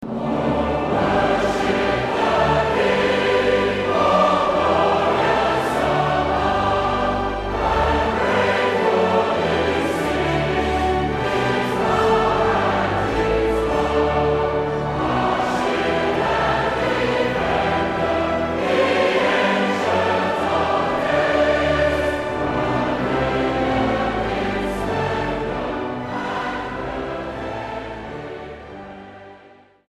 All Souls Orchestra - I Will Sing The Wondrous Story: The Best Of Prom Praise Hymns & Songs
STYLE: Hymnody